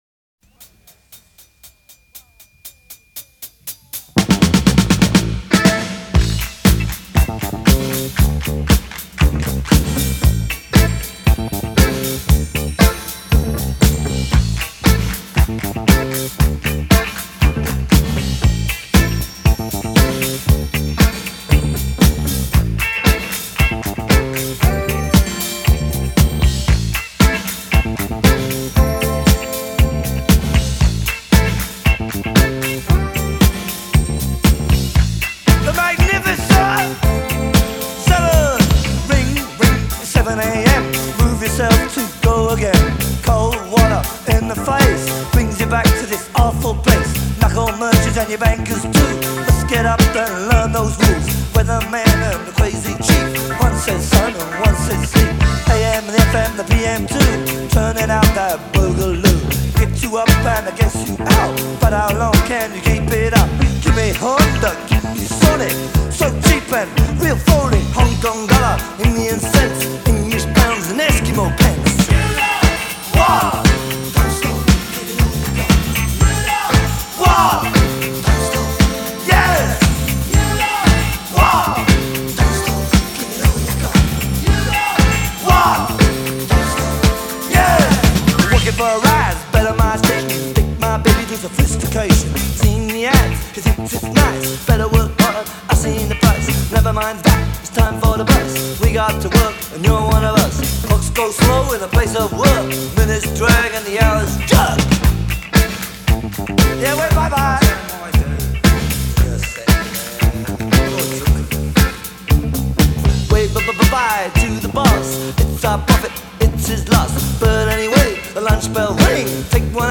punk